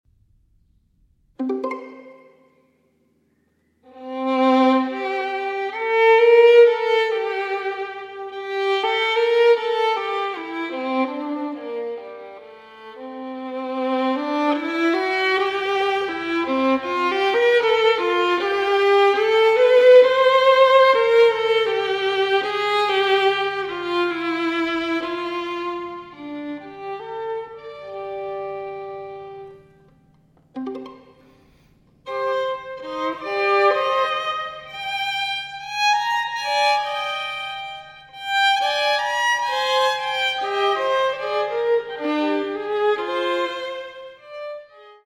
for Violin